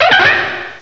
cry_not_karrablast.aif